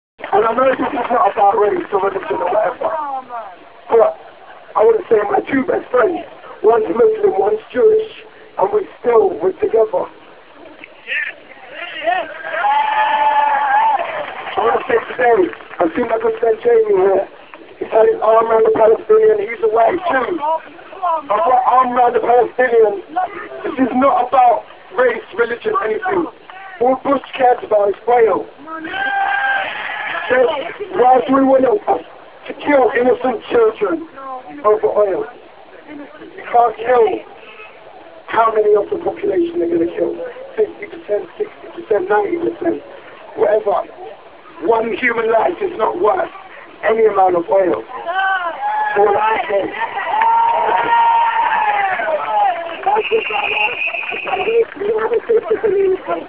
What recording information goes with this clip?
here for a short recording of me on the mic in front of 50,000 people, (my voice has nearly completely gone)